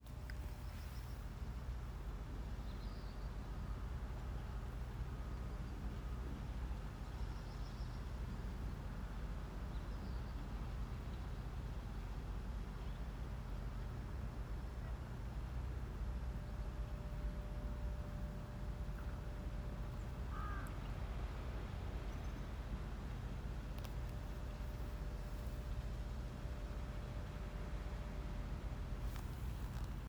Melnais erickiņš, Phoenicurus ochruros
Administratīvā teritorijaRīga
StatussDzirdēta balss, saucieni